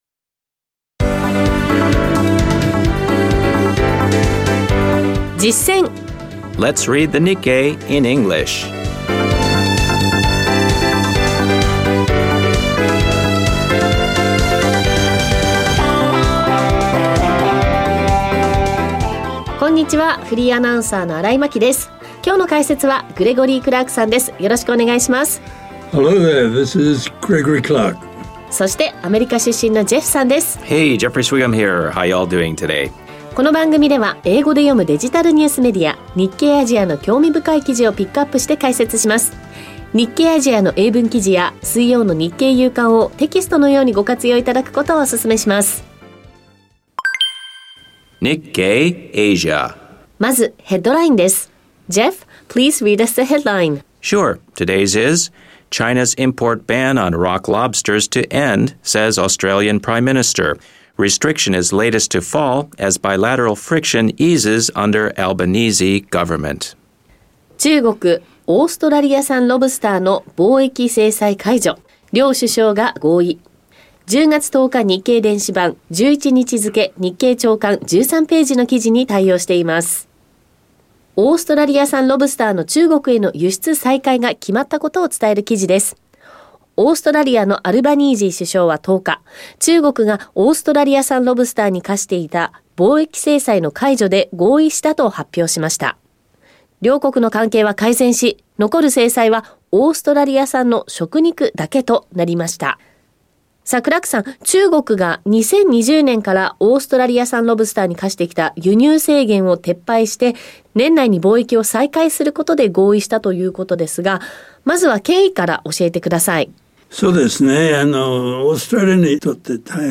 guides us through a powerful energy healing and meditation process